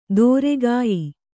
♪ dōregāyi